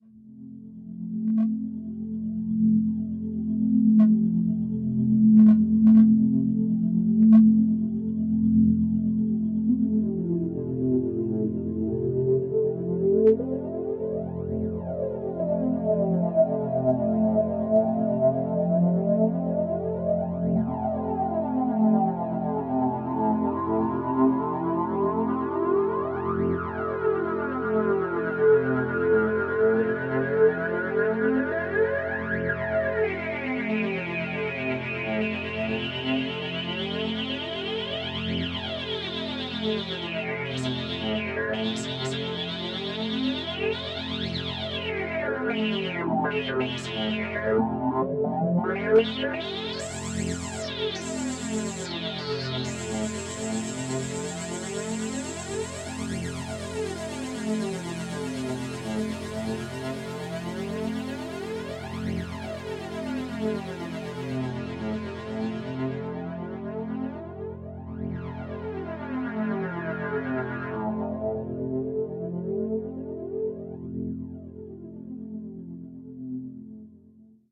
Звук рассеивания ядовитого газа с усыпляющим запахом